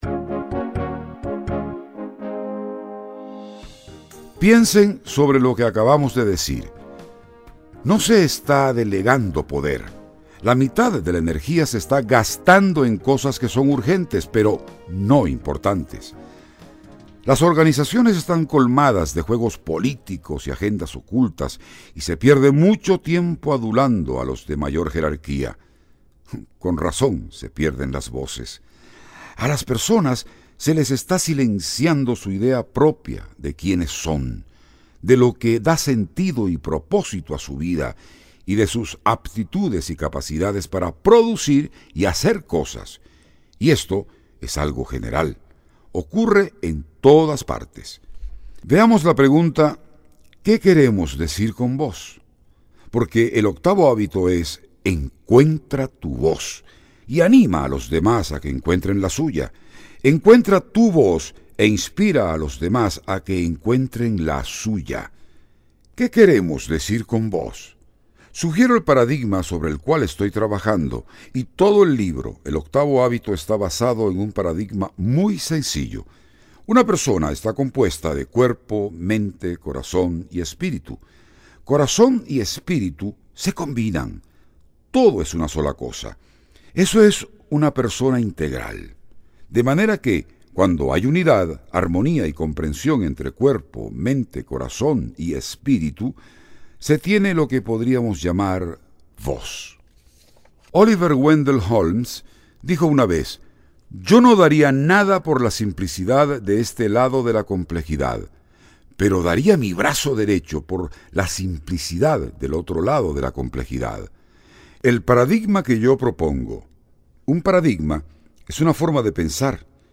Audio Libros